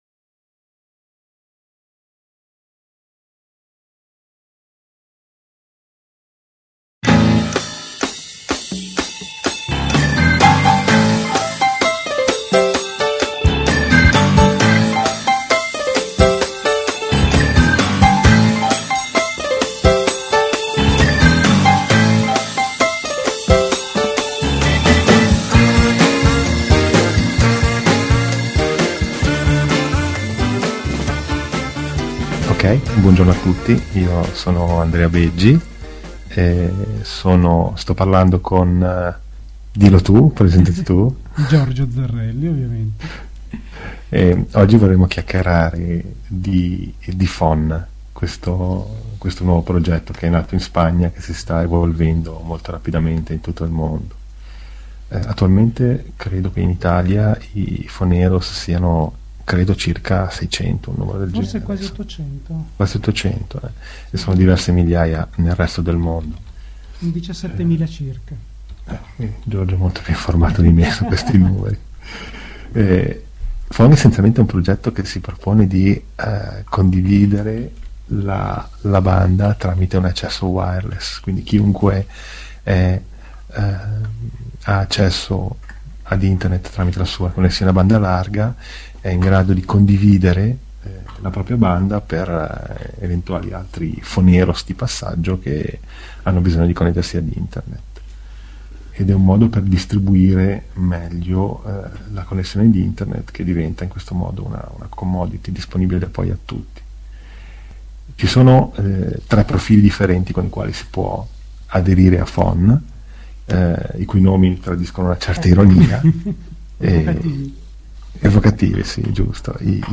E’ stata una discussione “a braccio”, molto piacevole.